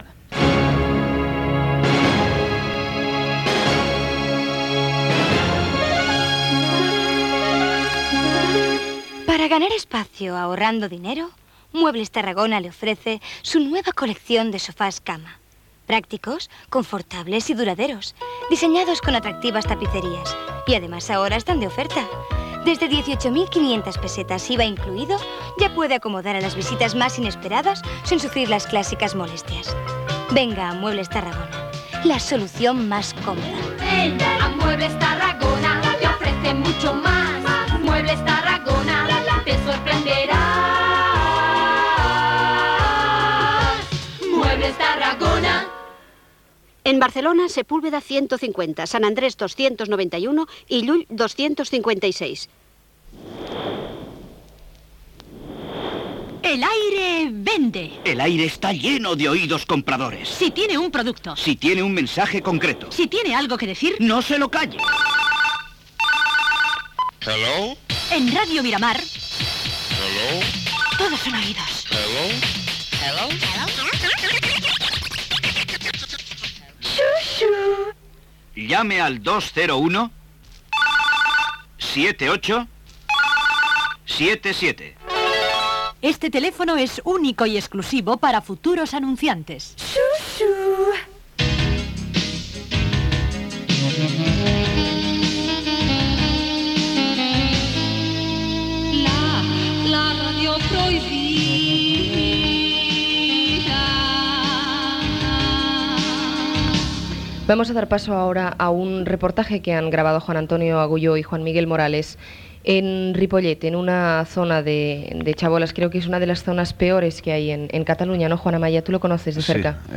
Publicitat, "El aire vende", indicatiu del programa, reportatge sobre una norantena de barraques a la vora del riu de Ripollet on viuen diverses famílies gitanes, trucada d'una oïdora